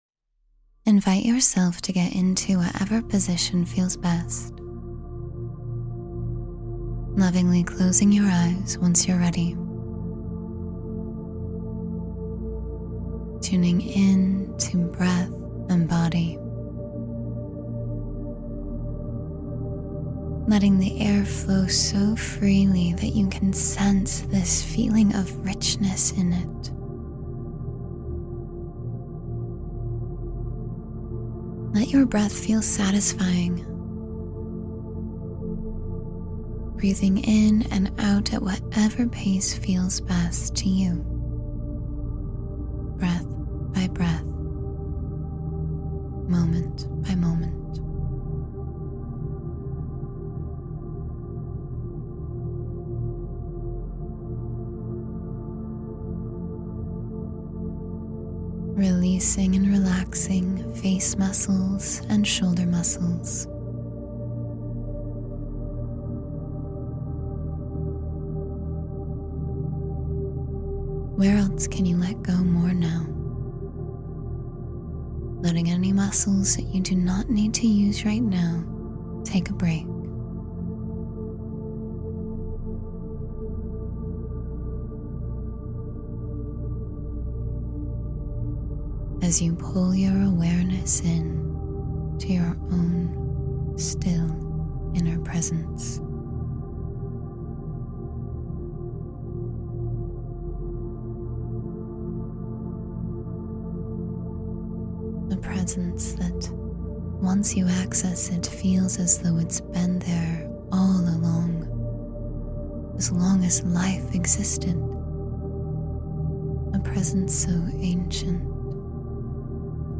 Open to Love and Let Yourself Be Loved — Guided Meditation for Heart Healing